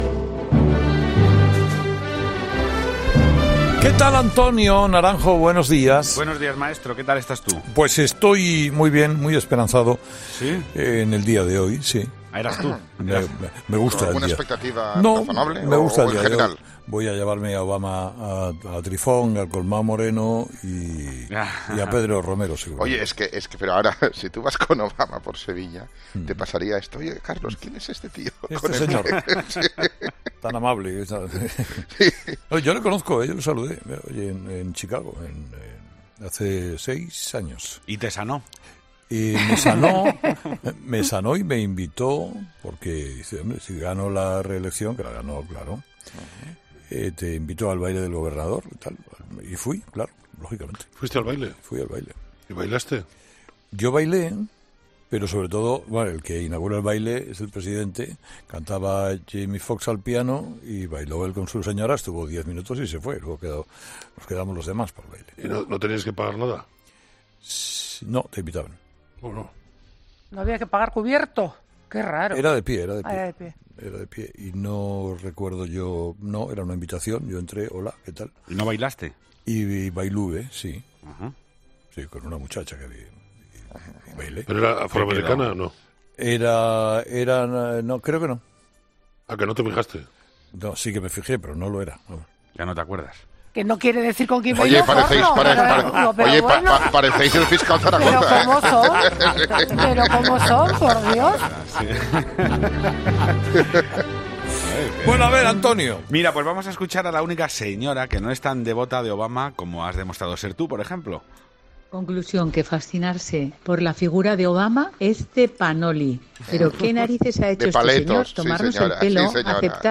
La tertulia de los oyentes se ha ido hoy por los cerros de Úbeda a cuento de Obama, pero ha merecido mucho la pena.